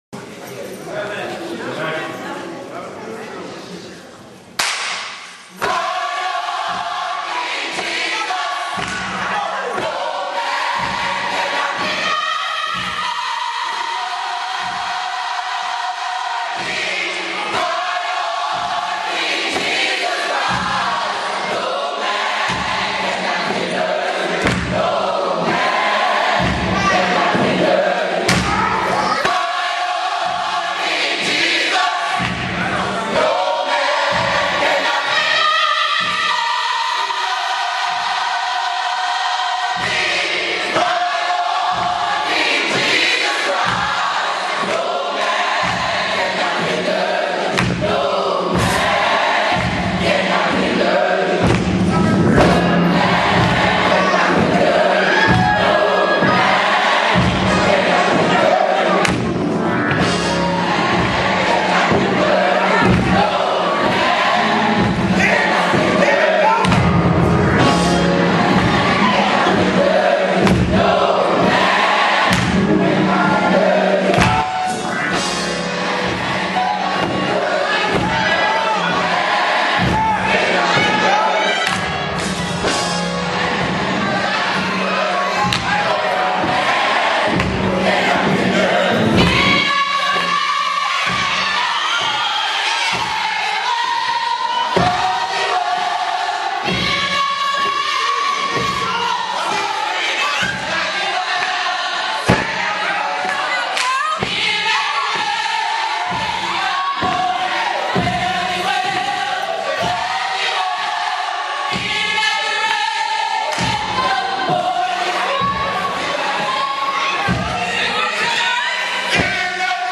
General Choir Reference Tracks
This module includes reference tracks only (lead included).
Reference Track (Lead Included)
In-HIS-Presence-Gospel-Choir-Ride-On-King-Jesus-1.mp3